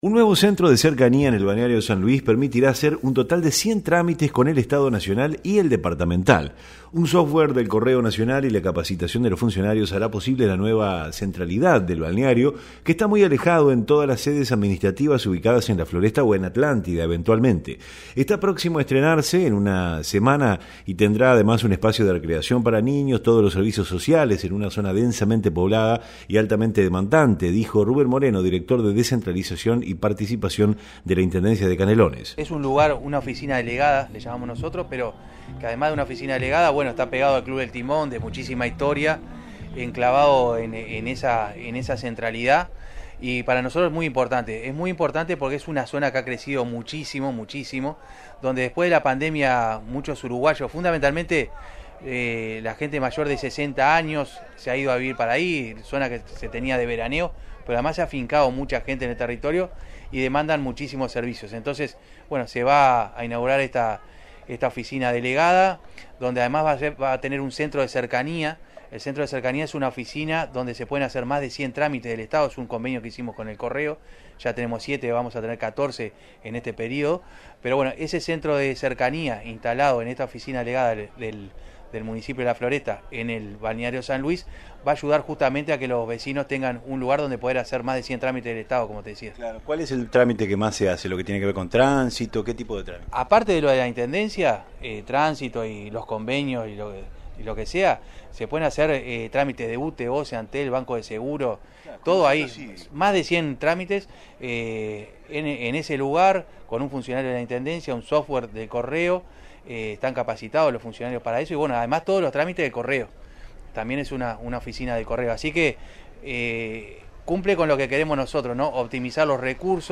Está próximo estrenarse y tendrá además un espacio de recreación para niños y todos los servicios sociales en una zona densamente poblada, dijo Ruben Moreno, director de Descentralización y Participación de la Intendencia de Canelones.
REPORTE-OFICINA-DELEGADA-SAN-LUIS.mp3